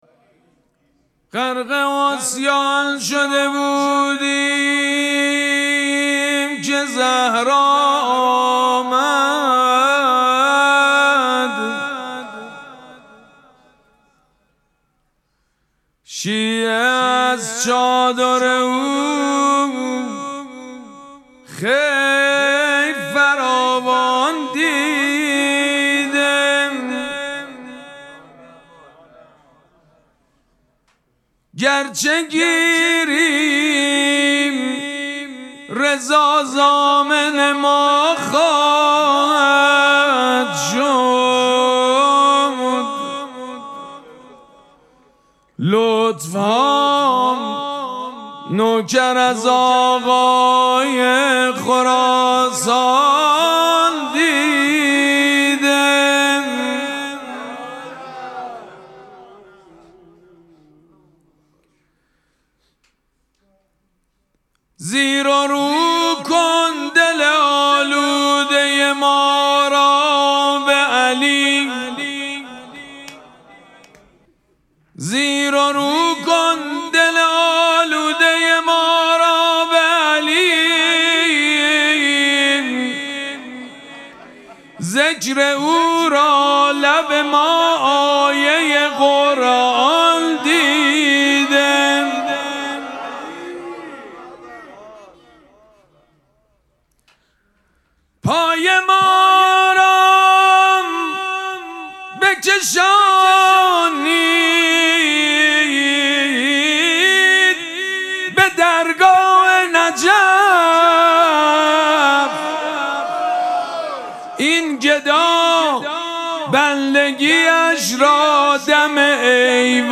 مراسم مناجات شب هجدهم ماه مبارک رمضان
حسینیه ریحانه الحسین سلام الله علیها
شعر خوانی